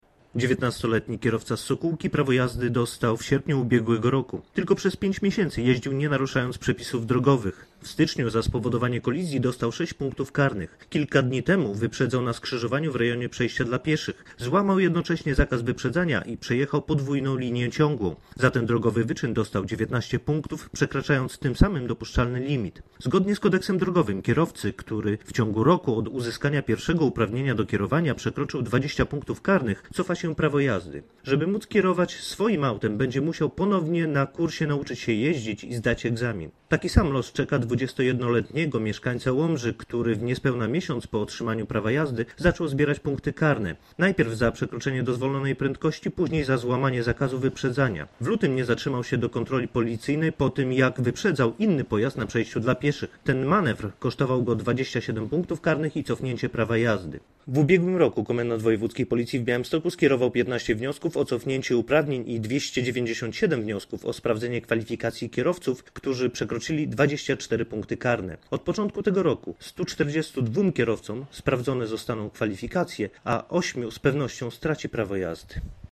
Nagranie audio Mówi podinsp.